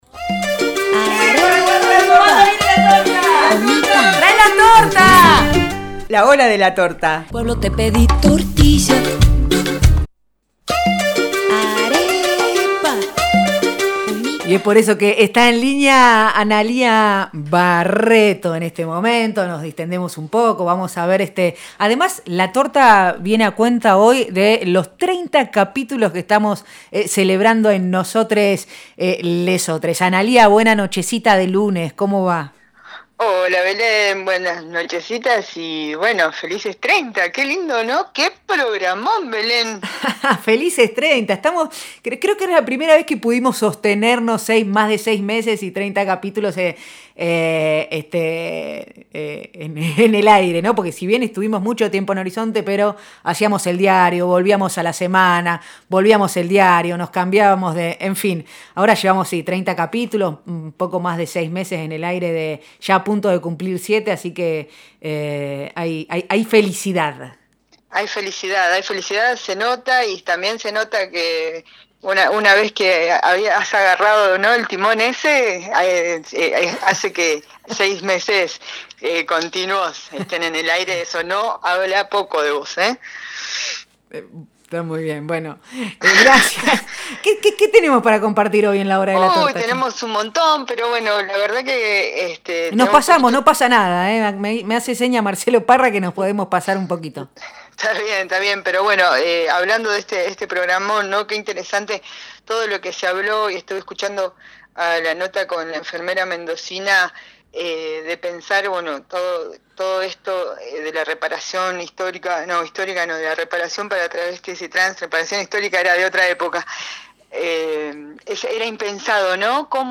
una charla entre tortas que intenta poner en escena discursos machistas y de odio que se expresan en la figura del “machirulo” o “la minita”, hijos sanos del patriarcado.
Nosotres les Otres, lunes de 18 a 20 por FM Horizonte 94.5